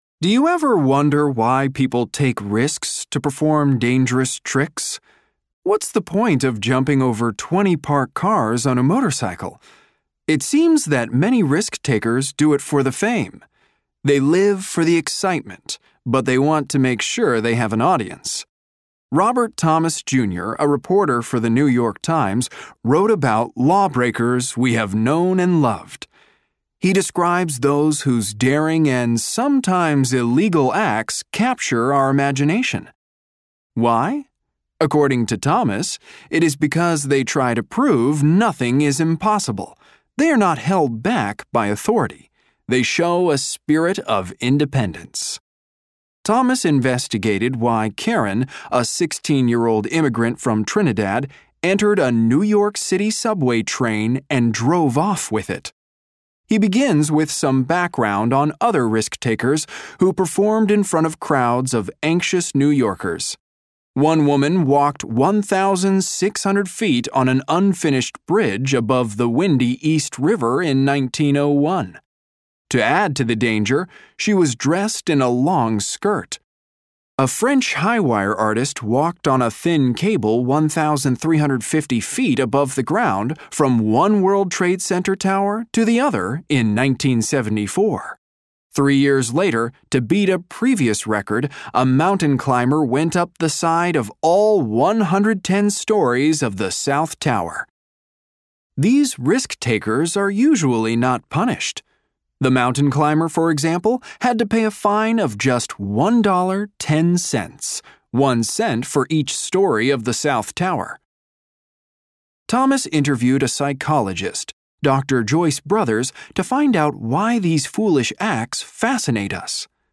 In this section, you will hear a monologue. You will hear the monologue twice.